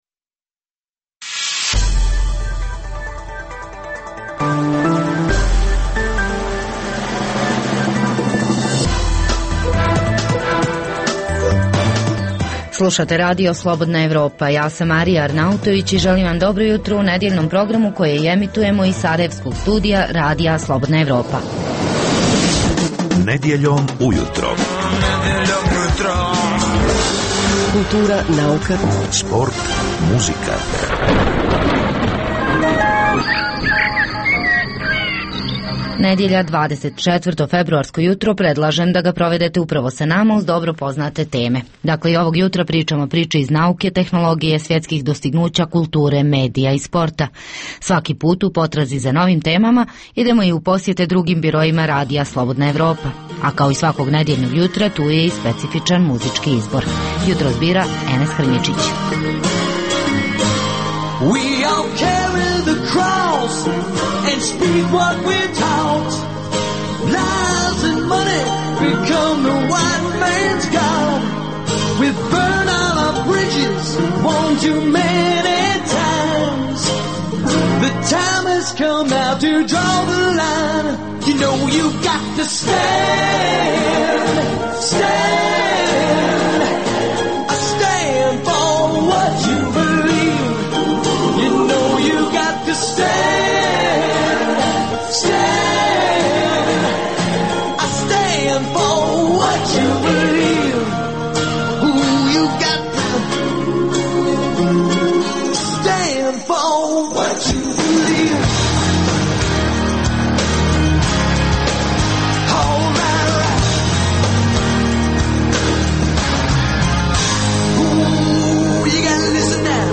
Poslušajte intervju sa zanimljivim gostom i, uz vijesti i muziku, pregled novosti iz nauke i tehnike, te čujte šta su nam pripremili novinari RSE iz regiona.